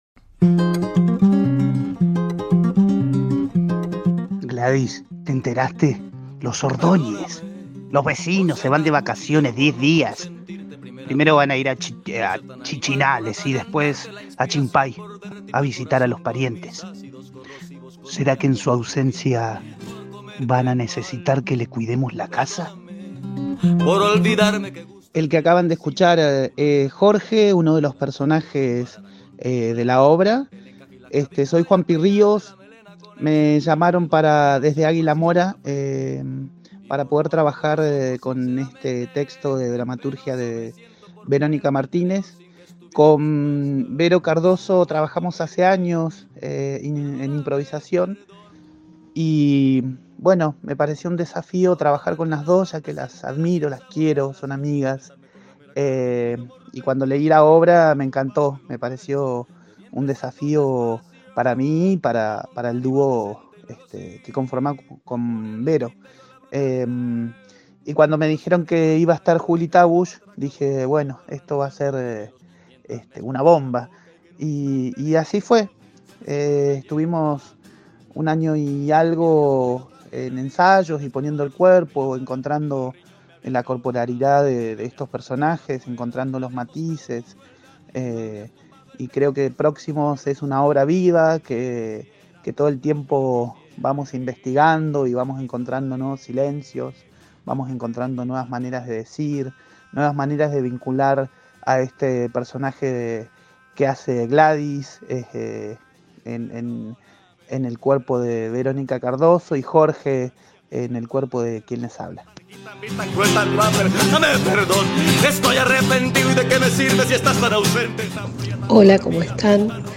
Escuchá a los protagonistas en «DIGO»